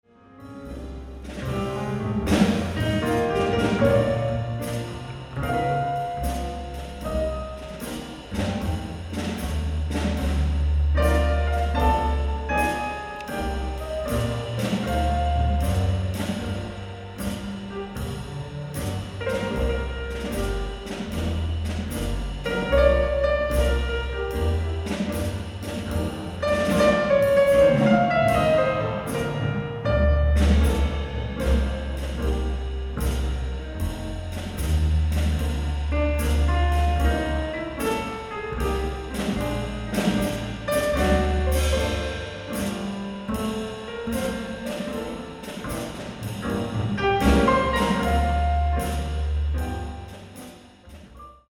ライブ・アット・パルコデッラムジカ音楽堂、ローマ、イタリア 07/18/2010
※試聴用に実際より音質を落としています。